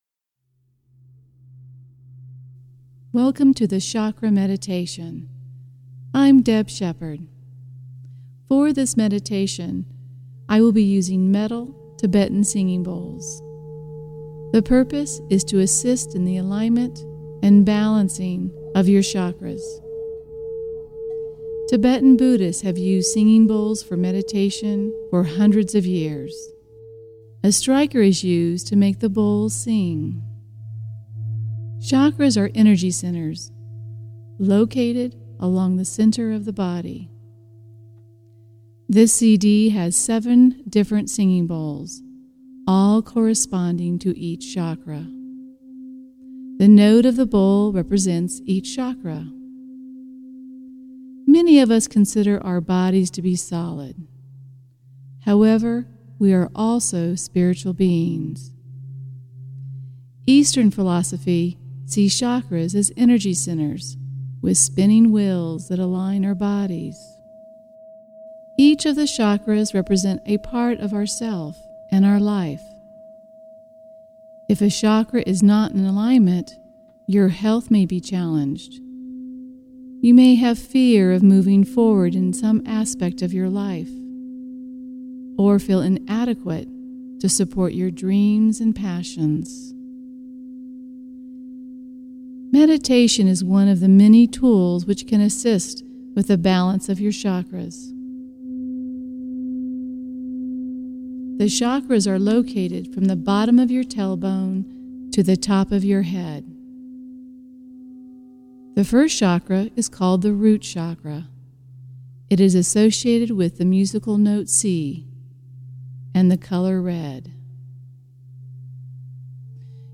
I have attached my Tibetan Singing Bowl Chakra Meditation just for you!
chakra-balancing-tibetan-bowl-meditation.mp3